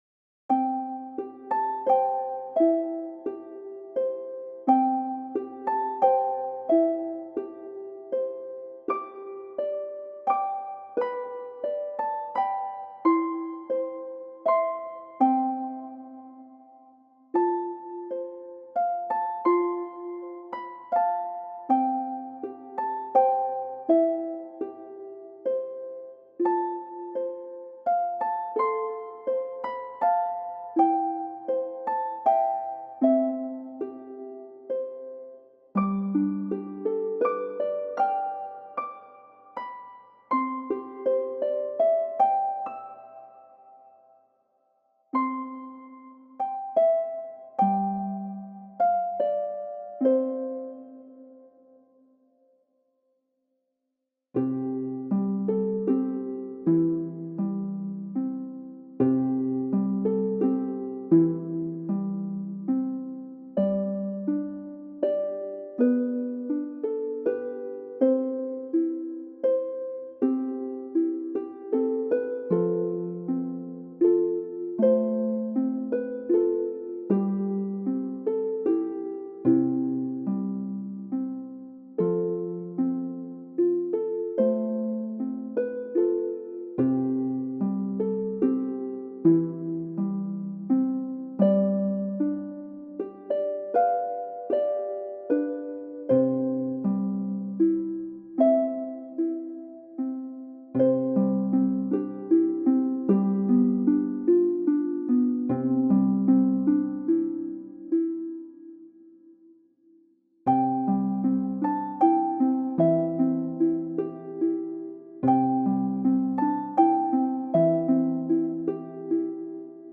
Musique de Noël relaxante : Nuit Silencieuse en harpe douce